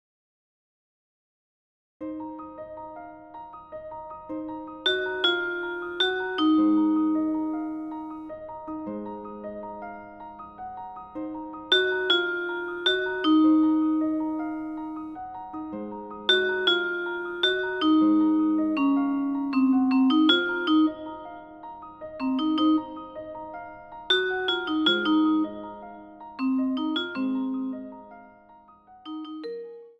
Instrumental Solos Trumpet
Demo